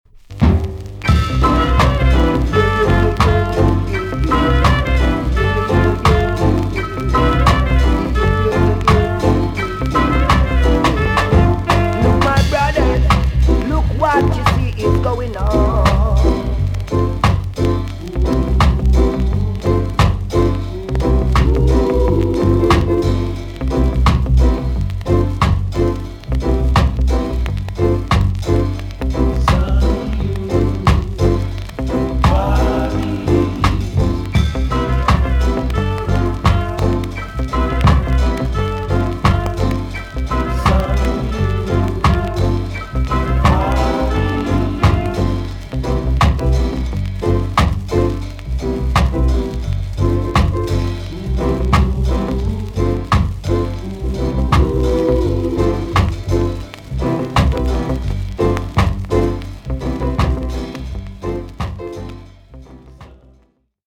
TOP >SKA & ROCKSTEADY
B.SIDE Version
VG ok 全体的にチリノイズが入ります。